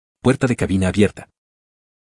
Ademas de las ventanas emergentes se recomienda agregar una alerta de sonido para identificar tanto visualmente de manera sonora y dar seguimiento mas oportuno a escenarios criticos.
PUERTA.mp3